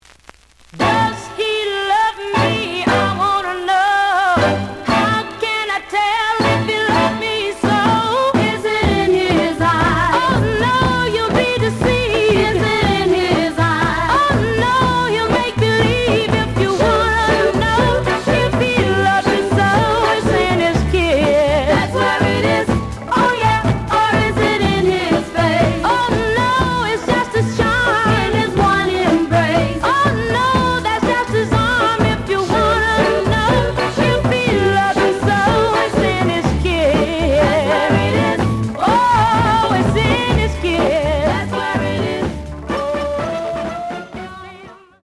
The audio sample is recorded from the actual item.
●Genre: Soul, 60's Soul
Some noise on both sides.